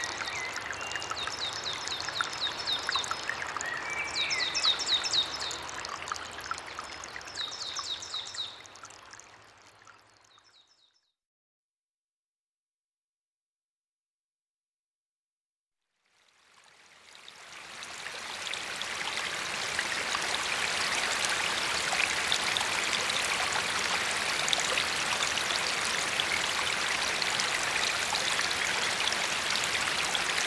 3 -- Naissance d'un petit ruisseau